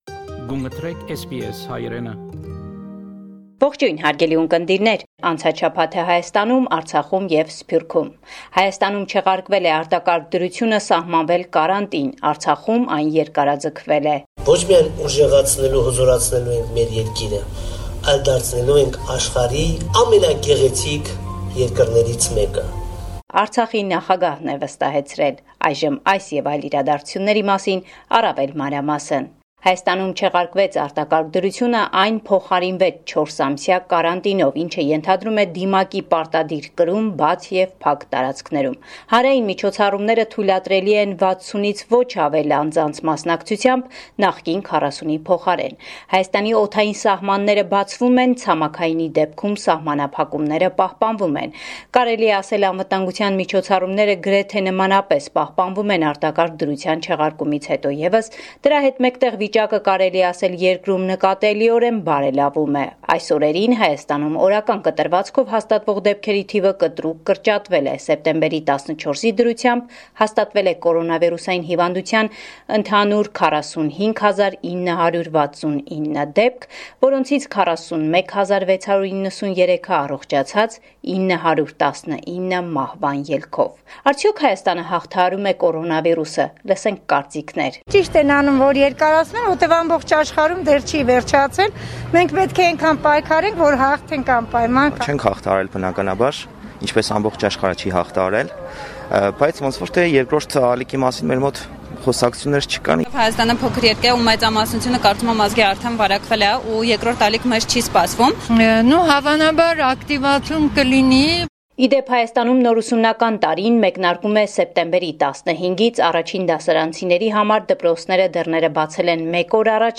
Latest News from Armenia – 15 September 2020